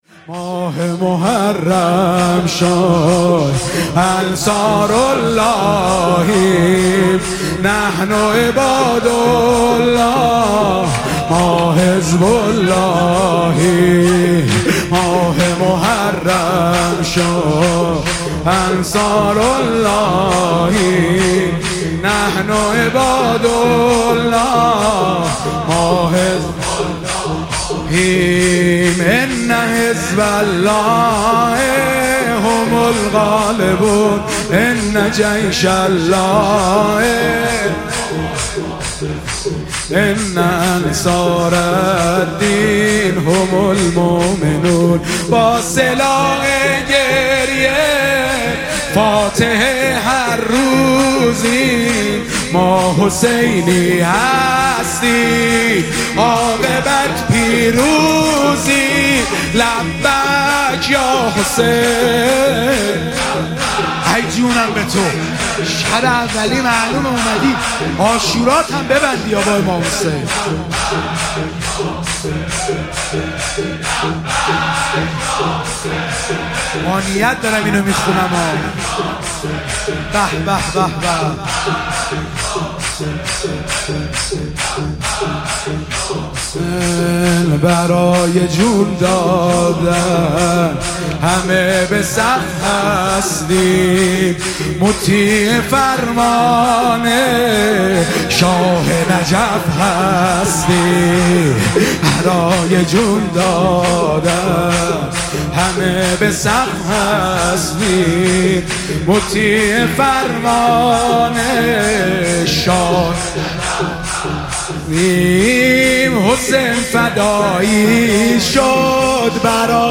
محرم98 - شور - ماه محرم شد انصارلله
شب اول محرم